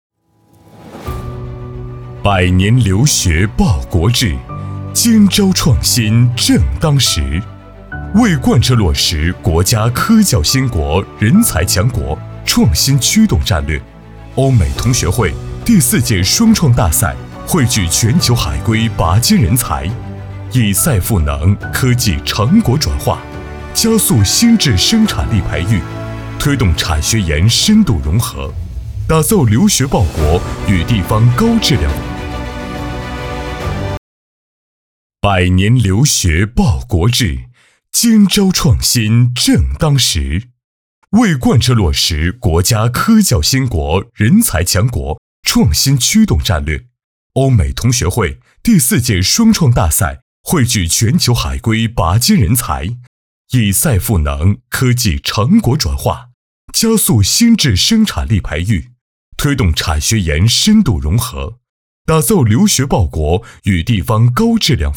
国语配音
男692-宣传片-欧美同学会第四届-1-.mp3